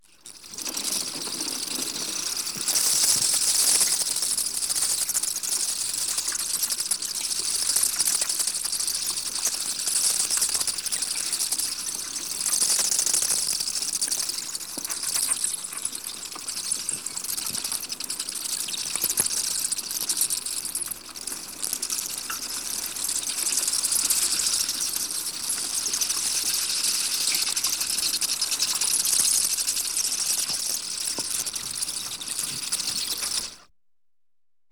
animal
Free Tail Bat Calls